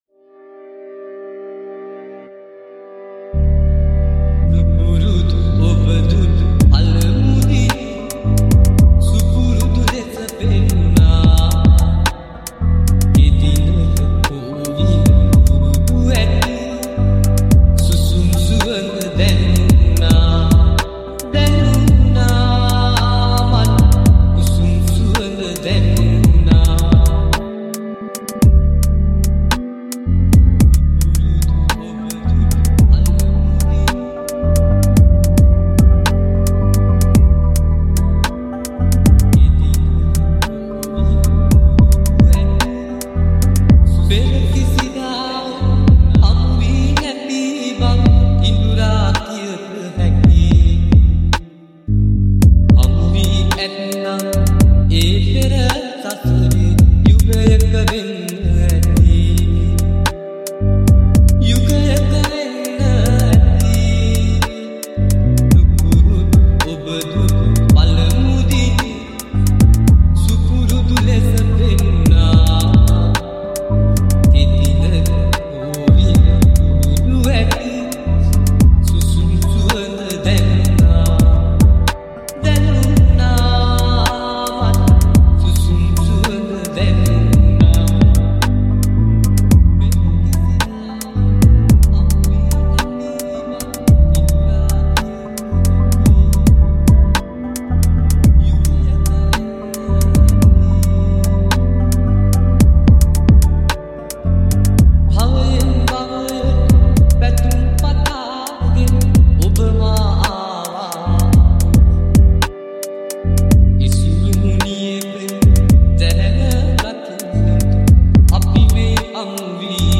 High quality Sri Lankan remix MP3 (2.7).